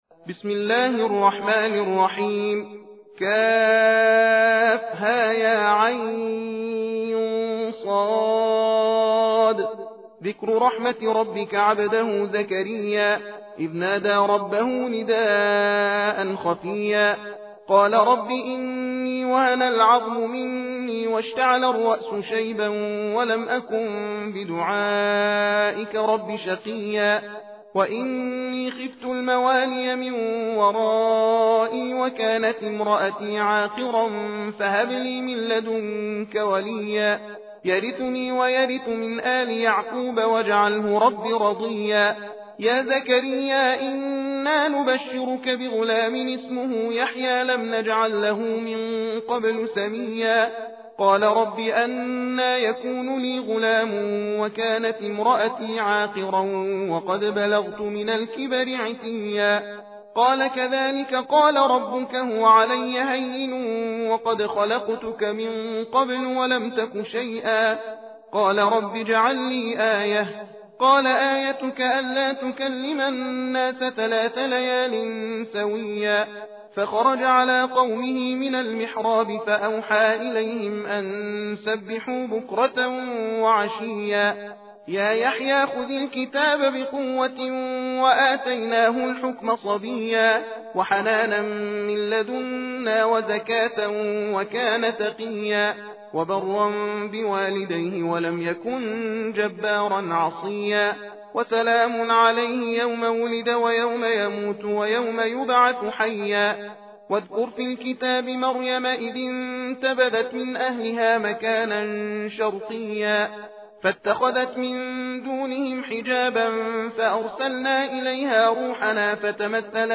تندخوانی (تحدیر) سوره مریم + متن و ترجمه همراه با فضیلت سوره مریم